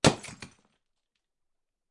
软木
描述：软木